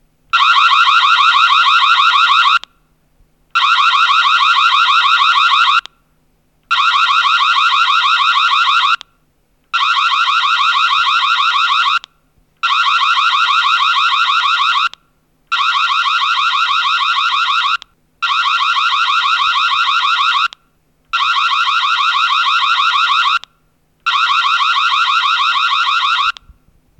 Trigger Sound Alarm
There's a siren sound that can scare people and make them run away 🙂
Here's a demonstration of the sound, just check it out below.
reolink-camera-siren.mp3